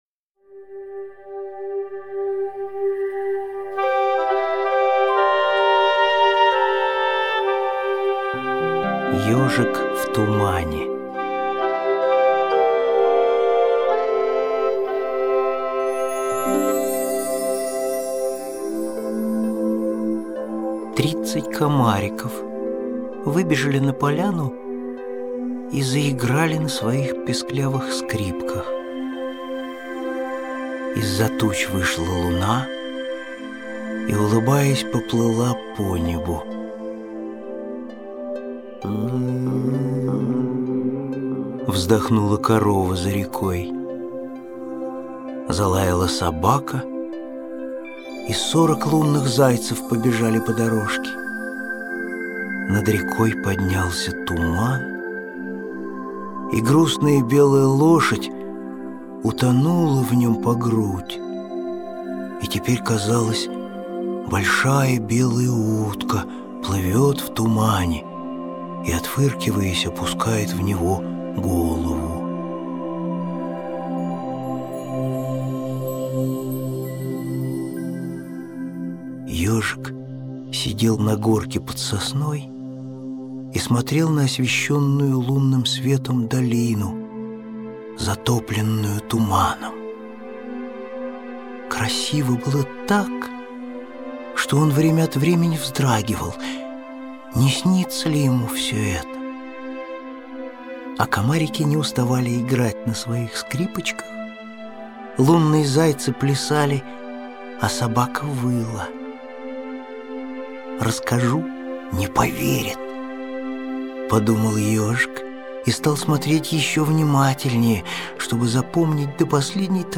Аудиокнига Ёжик в тумане. Кто это всё придумал? Сказки о жизни | Библиотека аудиокниг